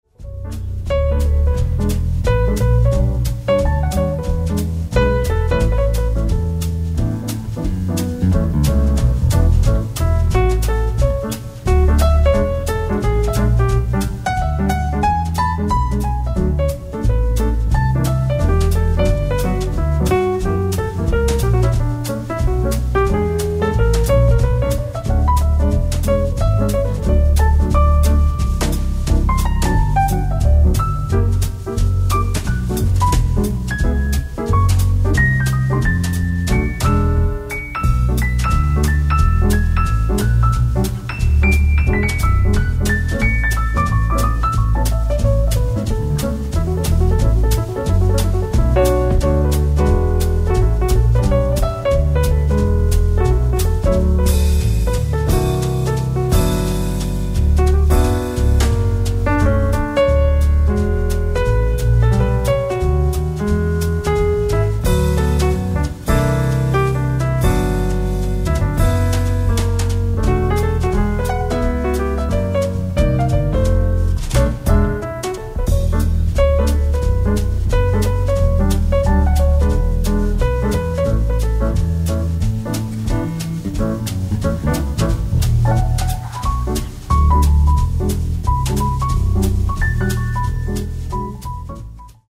ライブ・アット・マルシアック・ジャズ、マルシアック、フランス 08/10/1998
再放送されたデジタル音源よりオフィシャル級音質で収録！！
※試聴用に実際より音質を落としています。